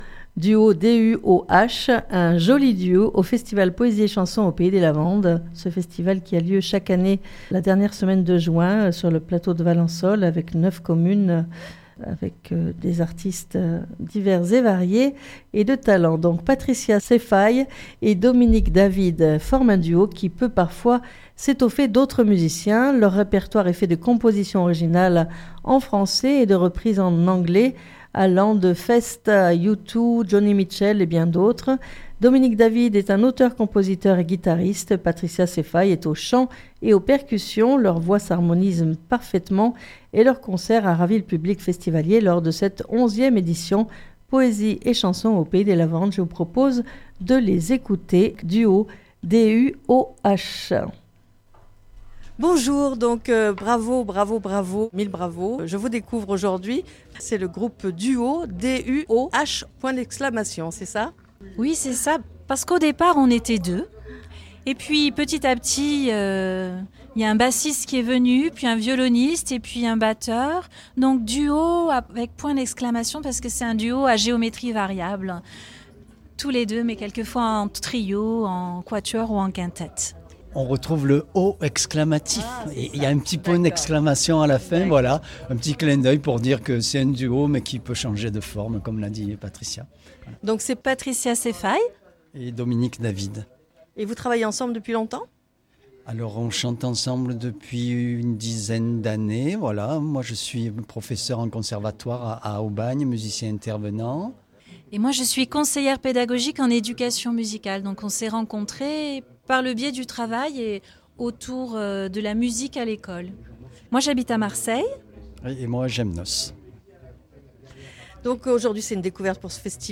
DuOH ! un joli duo au Festival Poèsie et Chansons au Pays des Lavandes.
Jrl Interview de DuOH au Festival Poésie et Chansons au Pays des Lavandes 2025.mp3 (14.06 Mo)